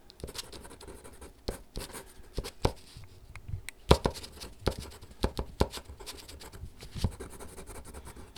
• writing with a ballpaint pen.wav
Writing and scribling with a pen on a wooden desk, recorded with Tascam DR 40.
writing_with_a_ballpaint_pen_4Y1.wav